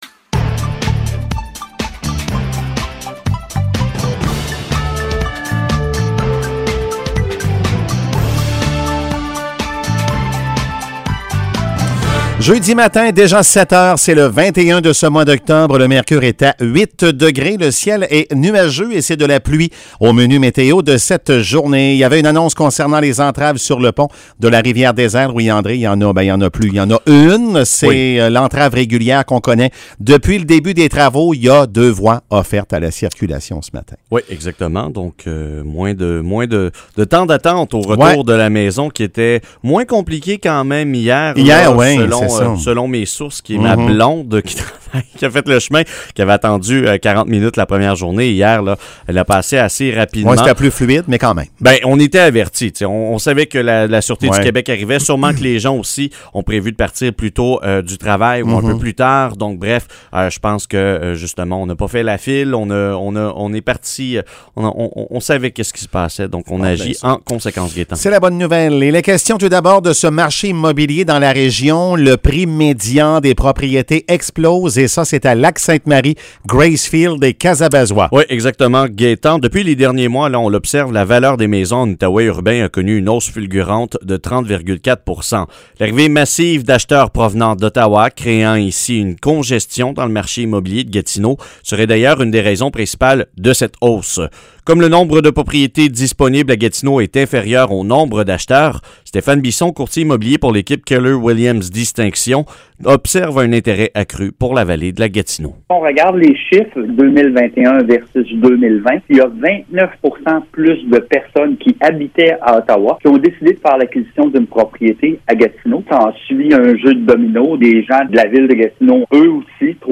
Nouvelles locales - 21 octobre 2021 - 7 h